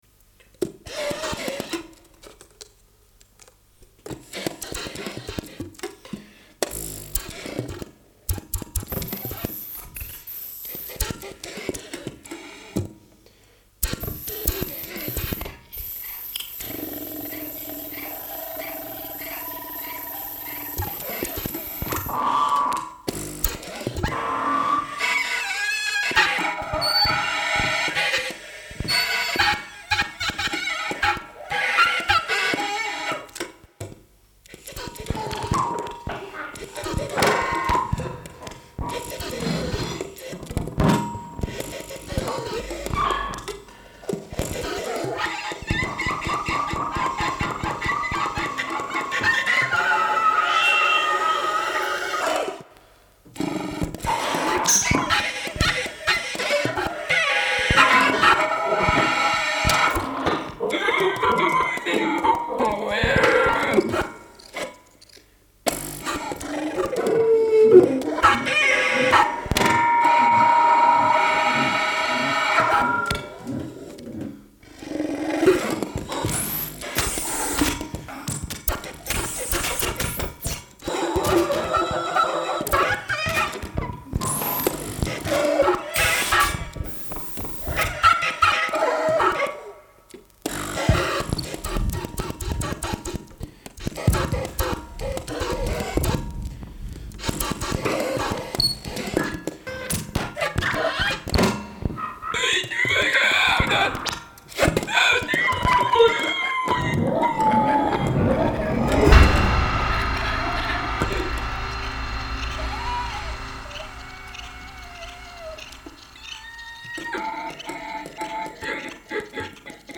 laptop, processed tapes, analog synth, radio
feedback and acousitc sax, resonant/feed-drums